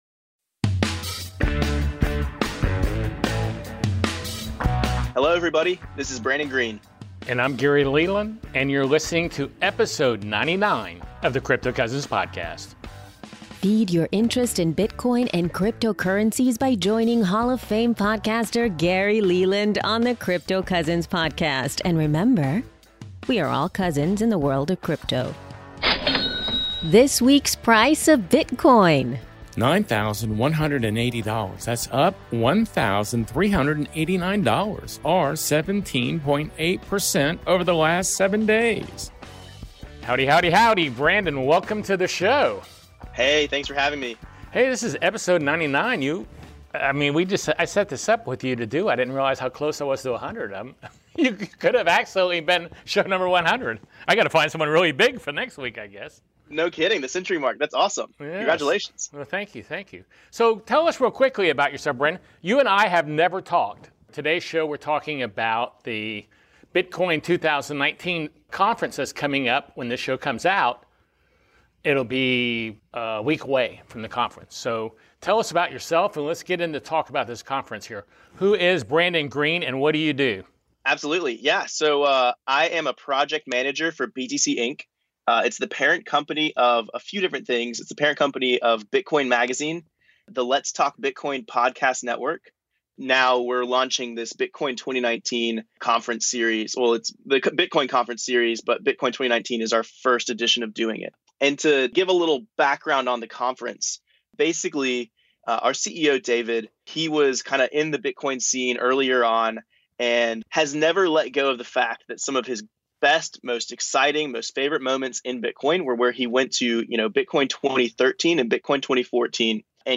Into and outro voice over